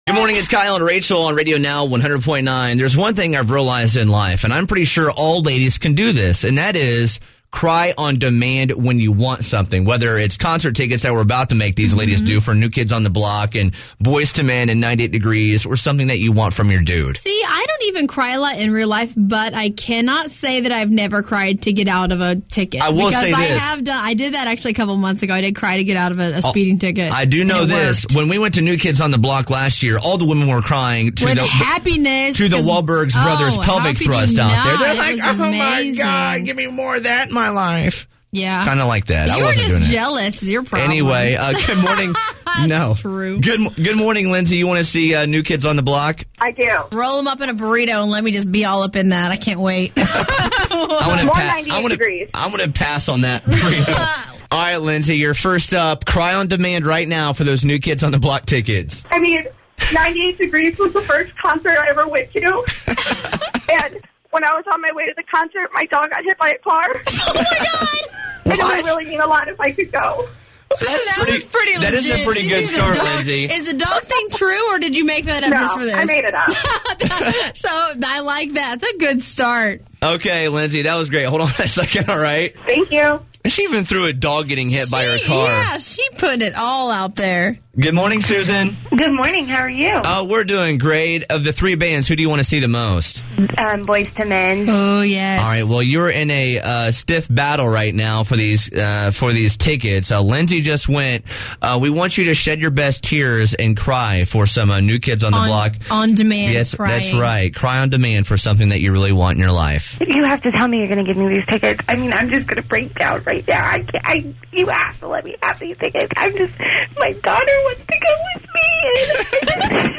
Ladies seems to be able to cry at the drop of a hat to get what they want! Listen to these ladies ‘cry’ in attempt to get NKOTB tix!